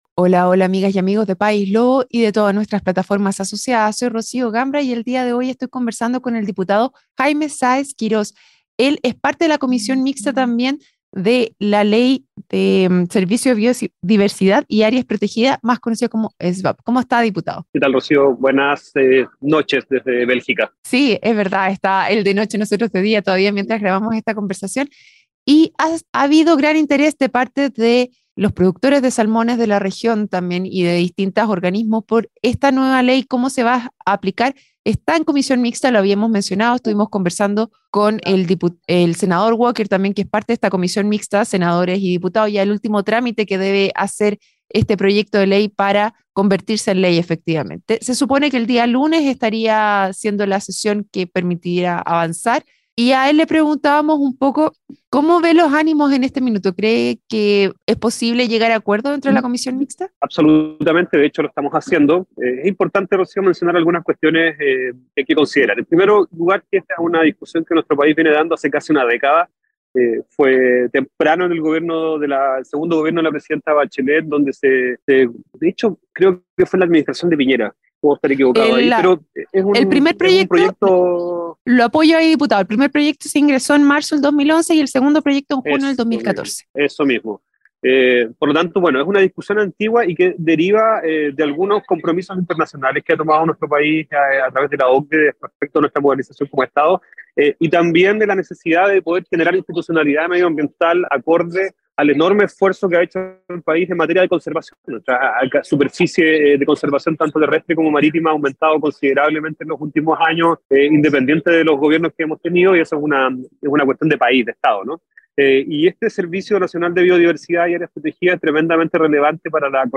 Durante la entrevista, se discutió sobre el impacto laboral que esta actividad puede tener y la posibilidad de alcanzar acuerdos para garantizar la preservación del medio ambiente.